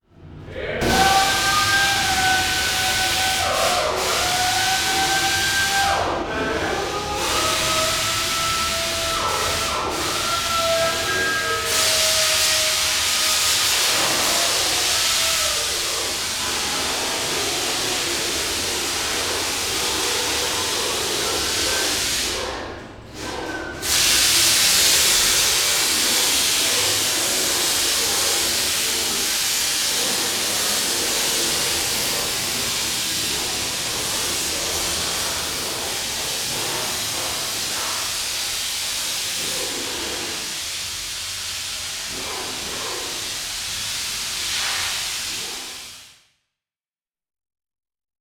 Ambiente de una fábrica con máquinas